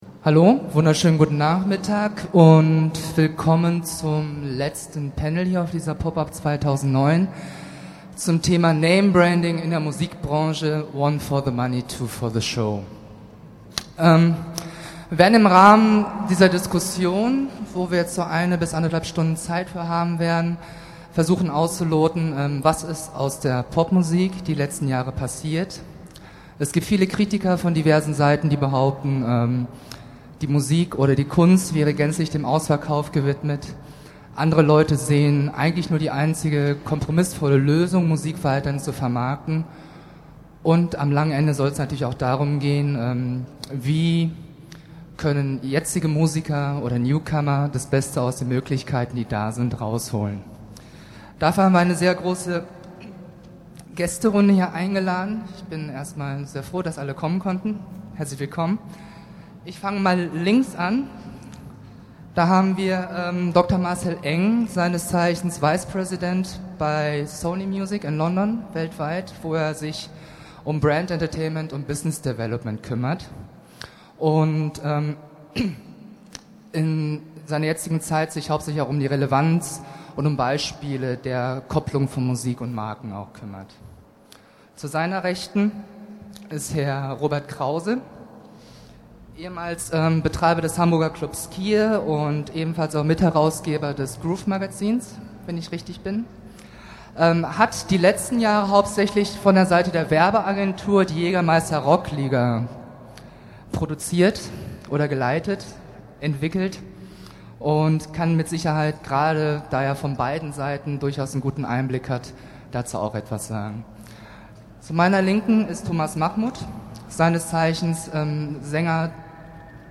Thema: Namebranding im Musikgeschäft Zeit und Ort: 16. Mai 2009, 16.30 Uhr im Volkspalast, Kuppelhalle
popup2009_panel_namebranding.mp3